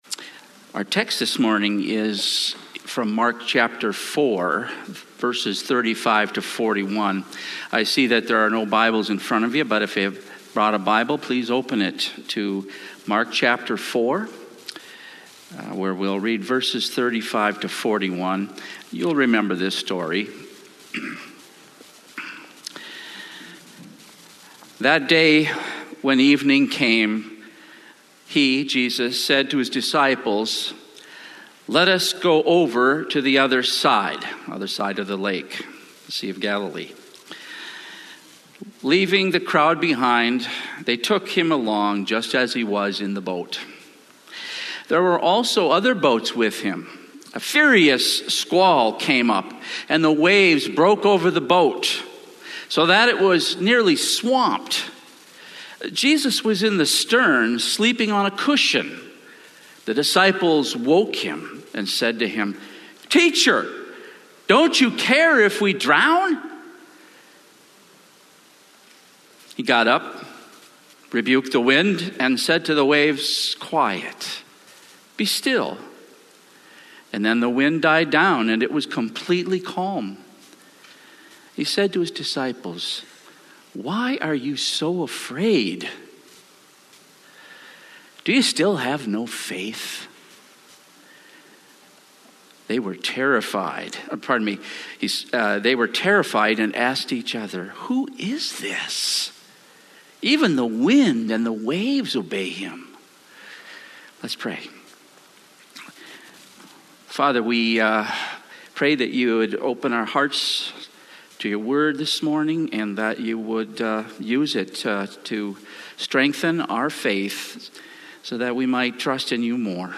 This week, guest speaker